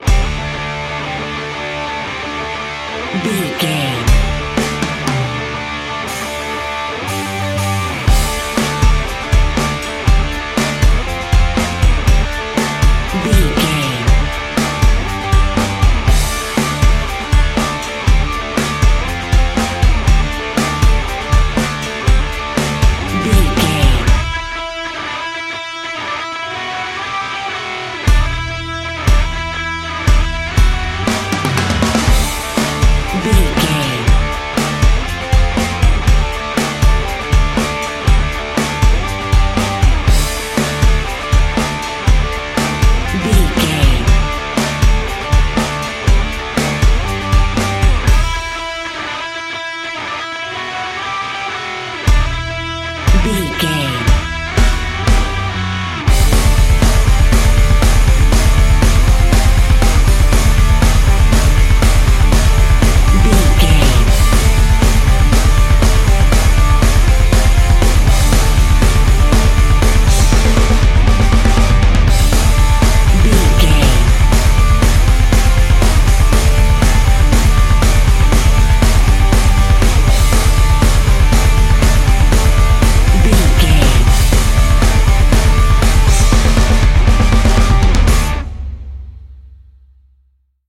Ionian/Major
SEAMLESS LOOPING?
WHAT’S THE TEMPO OF THE CLIP?
drums
electric guitar
bass guitar
Sports Rock
hard rock
lead guitar
aggressive
energetic
intense
nu metal
alternative metal